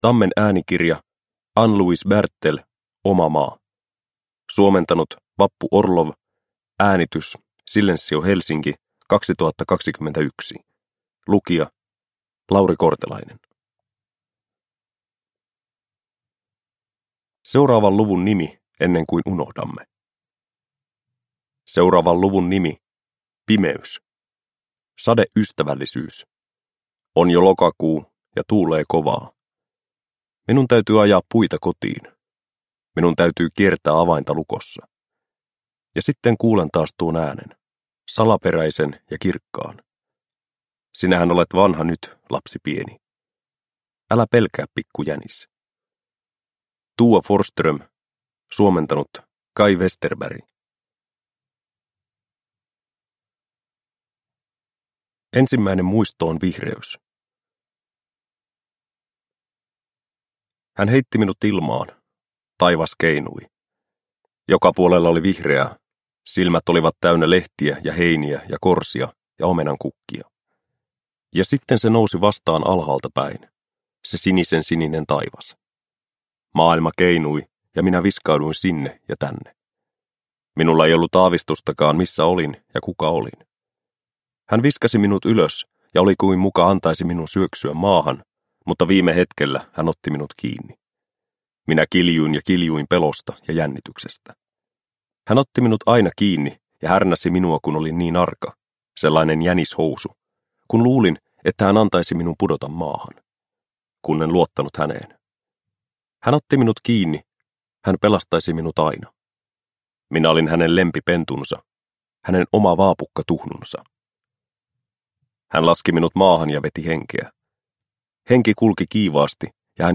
Oma maa – Ljudbok – Laddas ner